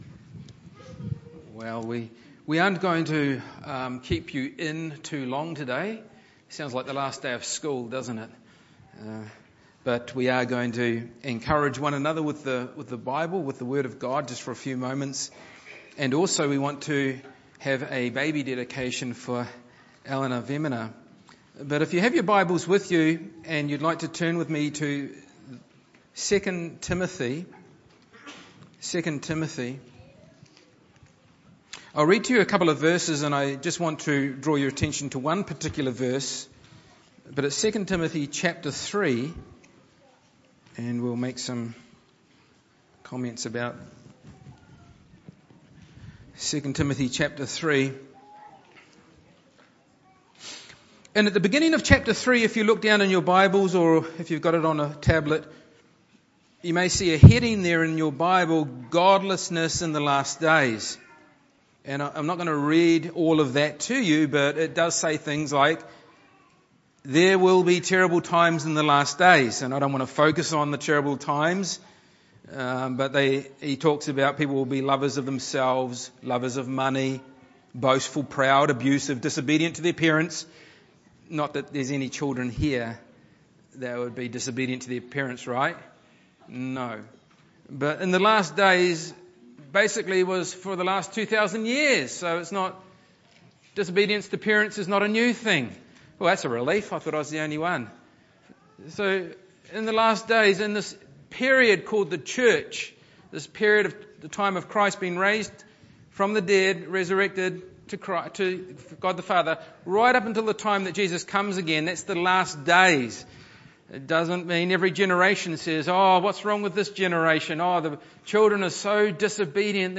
Kingdom Kids Celebration Service
Service Type: Sunday Morning